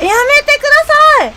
Worms speechbanks
Leavemealone.wav